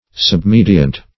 Submediant \Sub*me"di*ant\, n. (Mus.)
submediant.mp3